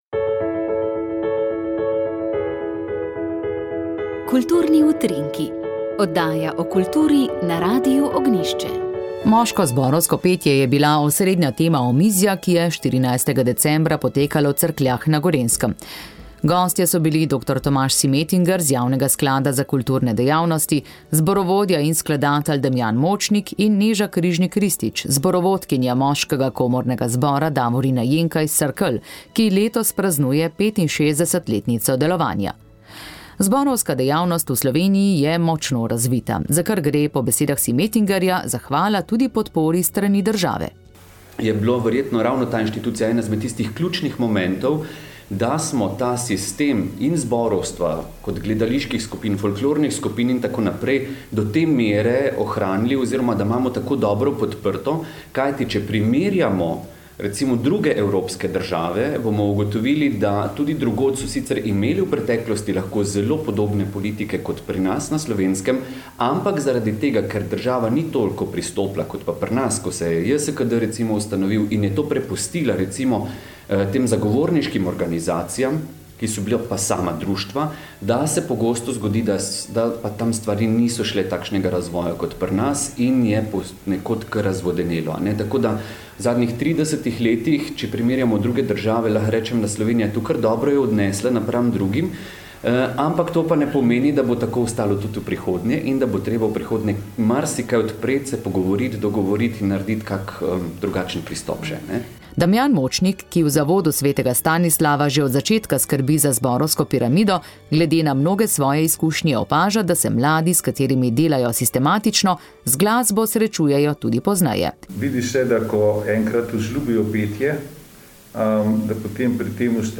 Božičnica in polnočnica iz cerkve Marijinega oznanjenja na Tromostovju v Ljubljani 25. 12.
Peli so člani ŽPZ p. Hugolina Sattnerja in solisti. Sodeloval je priložnostni orkester